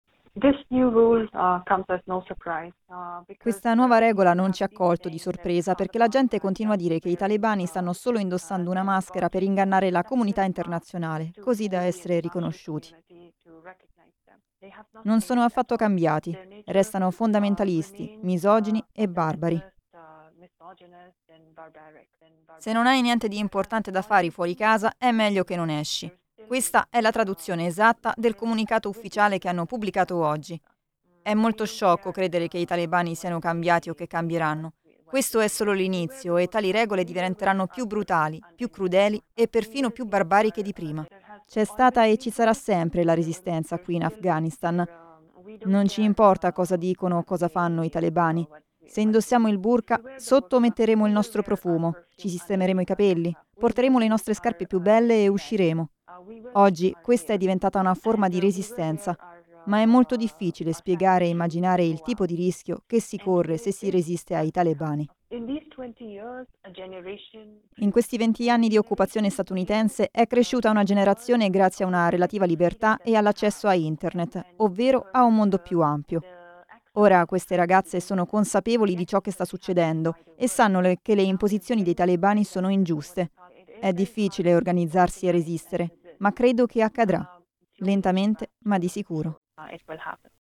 Ecco il commento di un’attivista aghana, di cui non sveliamo il nome per preservarne l’incolumità, in contatto con il Cisda – Coordinamento Italiano solidarietà donne afghane
RAGAZZA-AFGHANA-1930-Ritorno-del-burqa.mp3